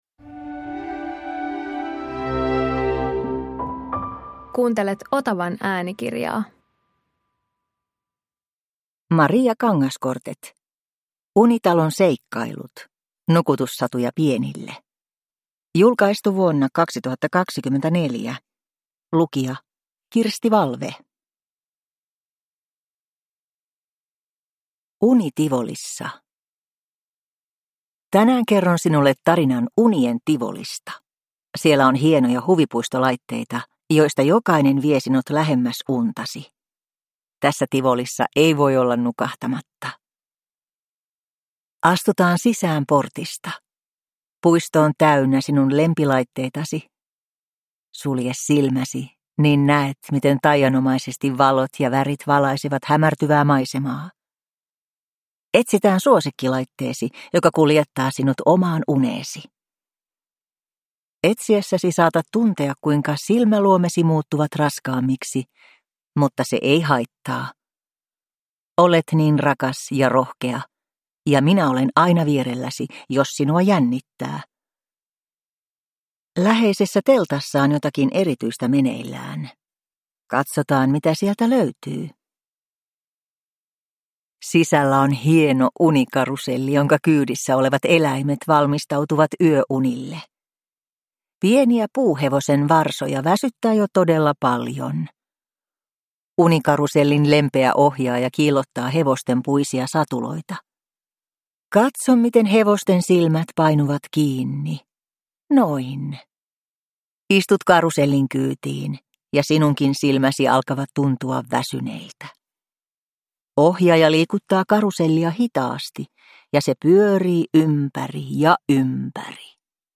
Unitalon seikkailut – Ljudbok